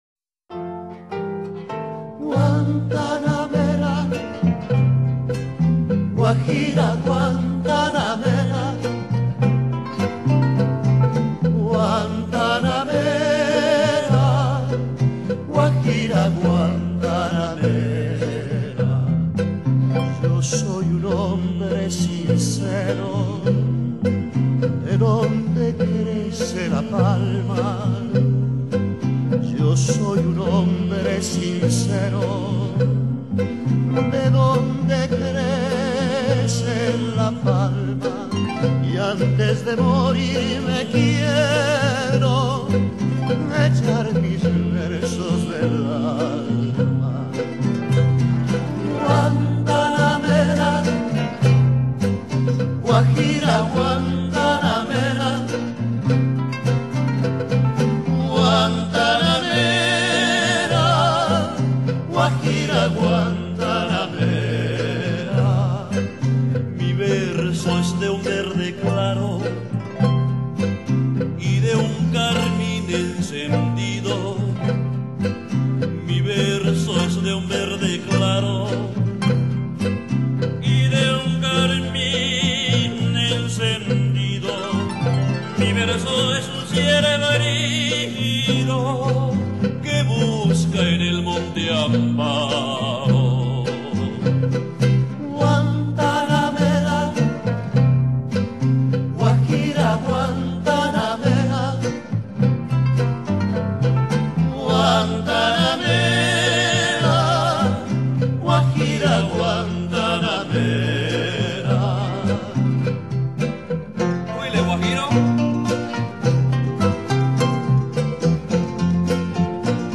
Genre: Latin, folklore, paraguayan